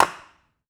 Skateboard Normal Landing.wav